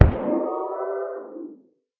guardian_death.ogg